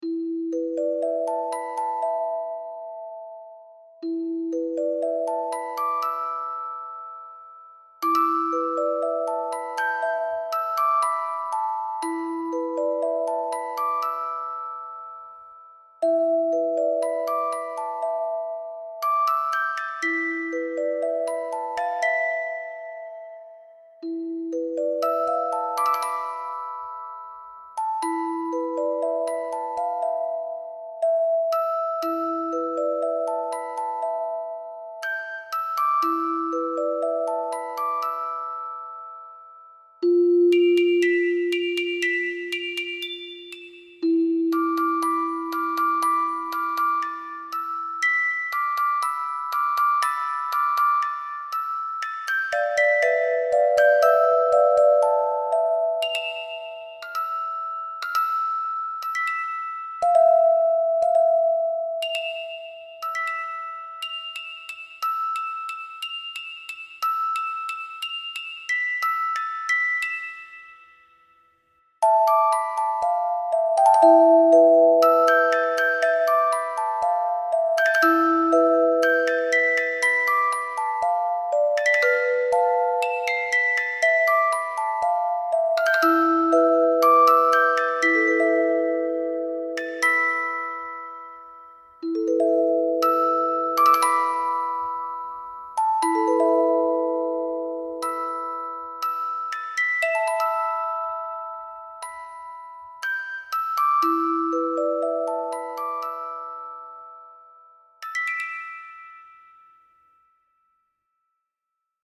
オルゴールのフルバージョンと、ショートバージョン、そして琴バージョンを公開しています。